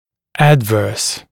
[‘ædvɜːs] [æd’vɜːs][‘эдвё:с] [эд’вё:с]неблагоприятный, вредный; побочный